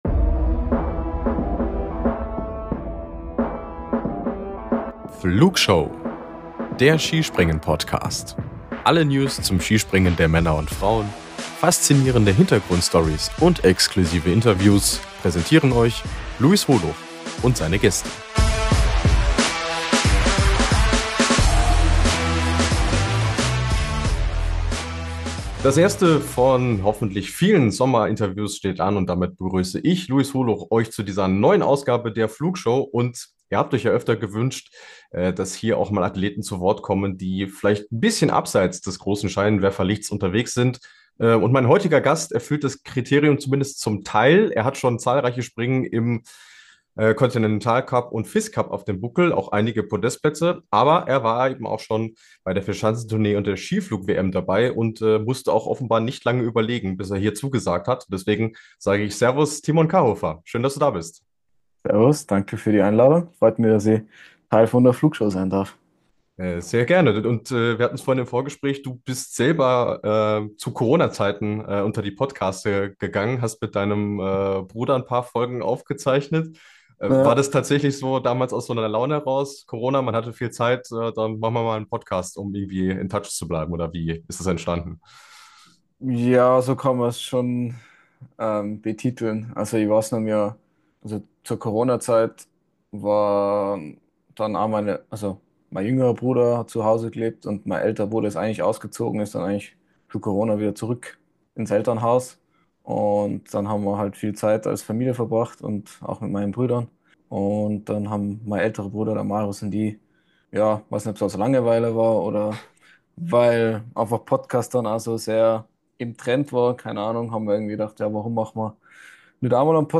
Interview ~ Wintersport Podcast